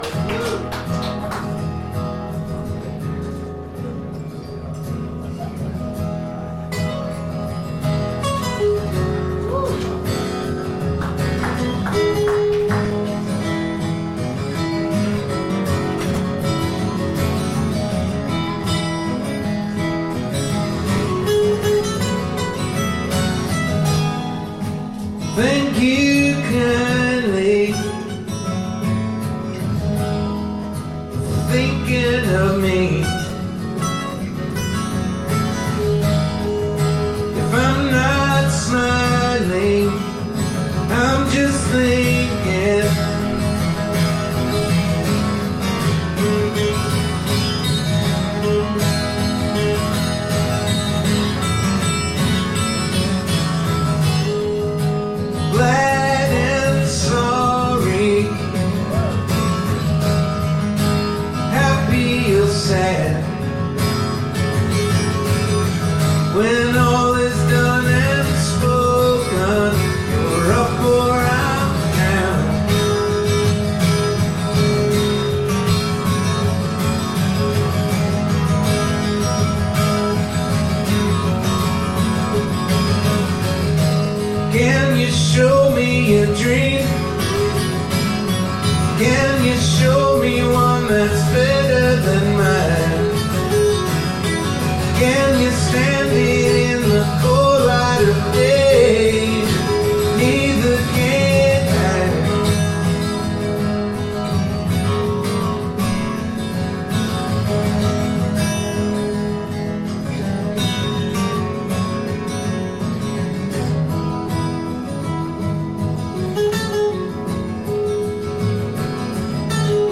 2017-11-13 New York, NY (interview)